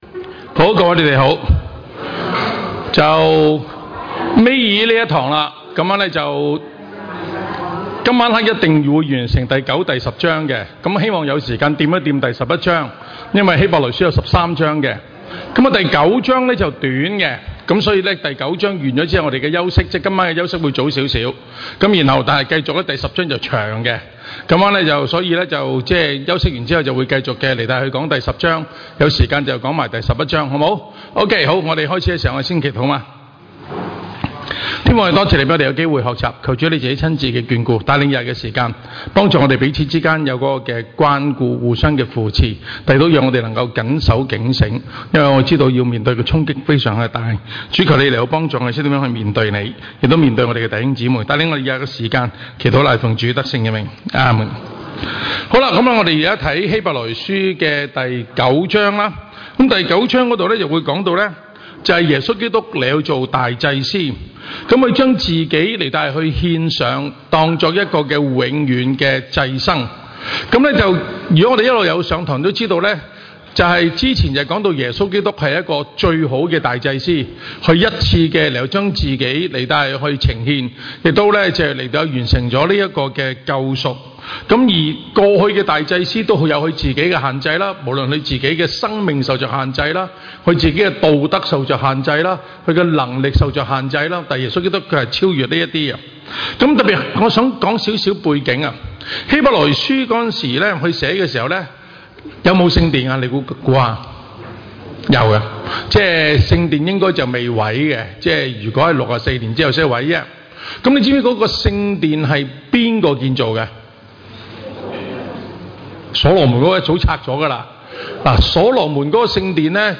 分類：聖經研究